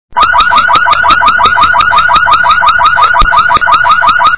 Index of /alarms
allarme_voiture1.wav